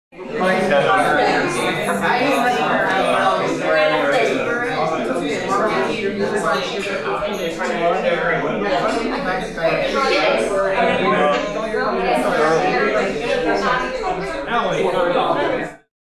Small Group Talking Sound Effect
A group of about ten people talks and discusses in a medium-sized room. The conversation unfolds in a friendly and casual atmosphere, creating a natural social ambiance. Perfect for videos, films, games, podcasts, and background scenes that need realistic group chatter.
Small-group-talking-sound-effect.mp3